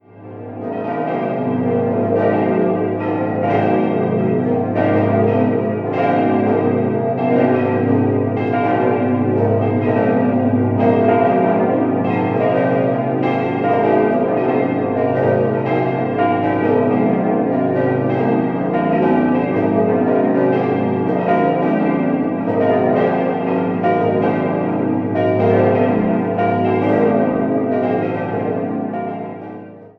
Die bedeutenden Stuckaturen stammen von Georg Schmuzer. 6-stimmiges Geläut: a°-h°-cis'-e'-fis'-a' Alle Glocken wurden im Jahr 1947 vom Bochumer Verein für Gussstahlfabrikation gegossen.